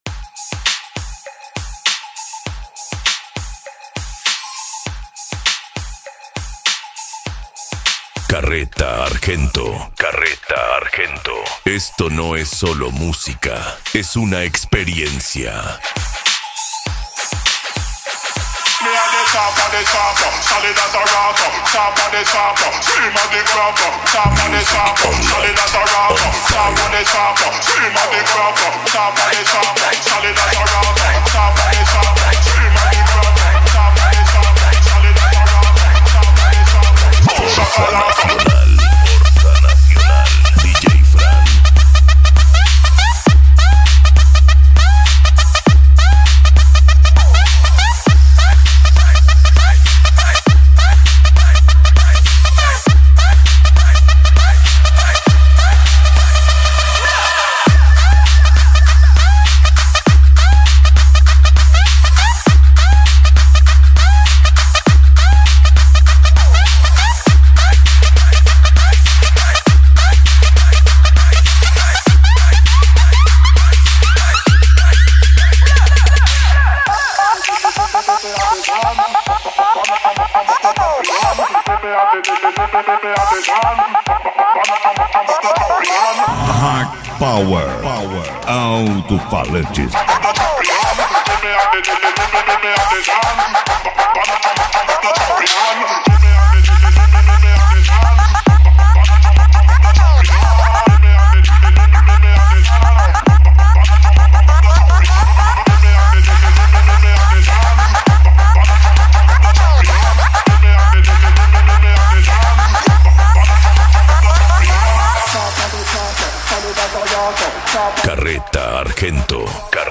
Bass
Psy Trance
Racha De Som
Remix